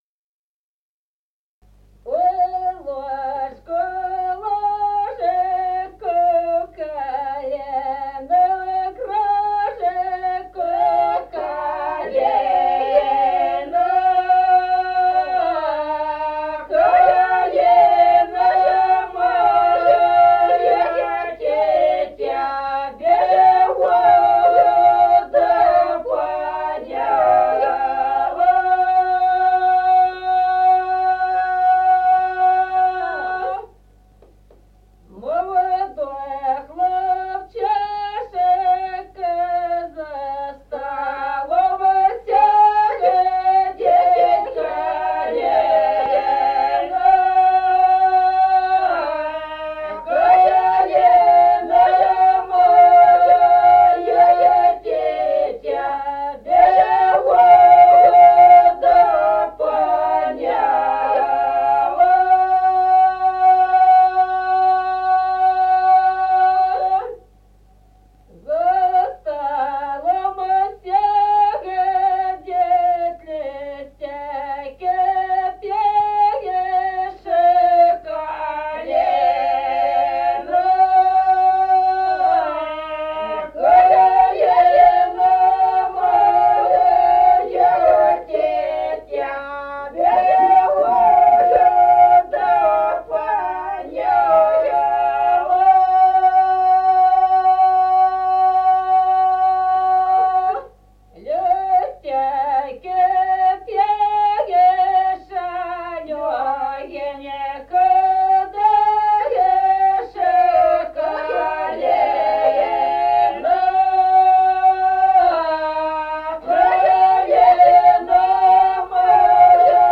Народные песни Стародубского района «Во лужку, лужку», новогодняя щедровная.
с. Остроглядово.